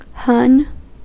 Ääntäminen
US
IPA : /hʌn/